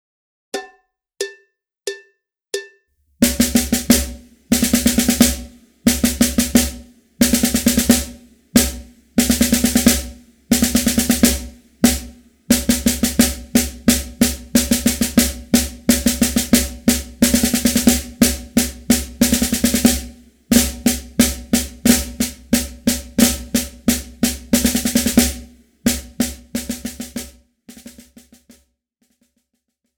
Besetzung: Schlagzeug
07 - 7-Stroke-Roll
07_-_7-Stroke-Roll.mp3